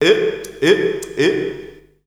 Index of /90_sSampleCDs/Voices_Of_Africa/VariousPhrases&Chants
21_MaleRhythmicVocalPerc.WAV